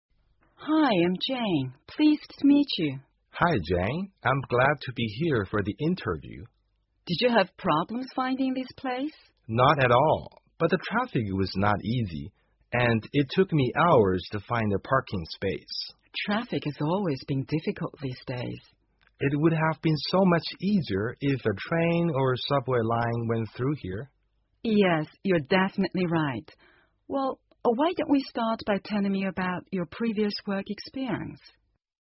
2015年高考(湖北卷)英语听力真题 长对话(2) 听力文件下载—在线英语听力室